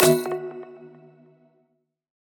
match-join.mp3